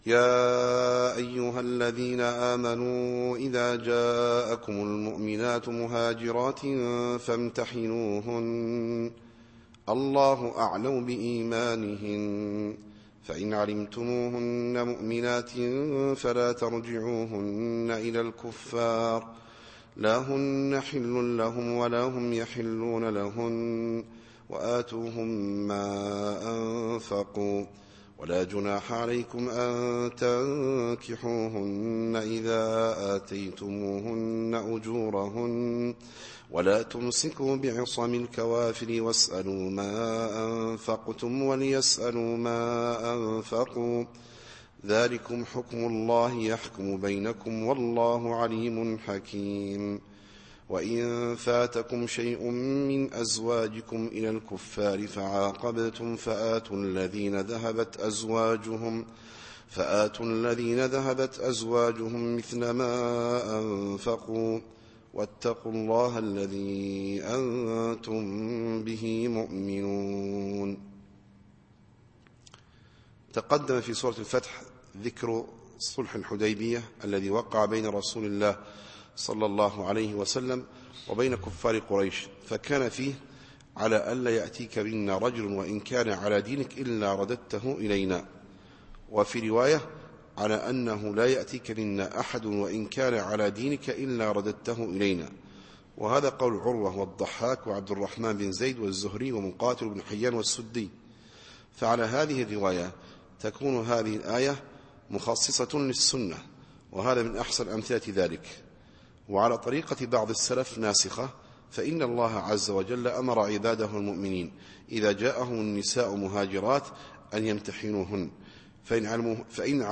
التفسير الصوتي [الممتحنة / 10]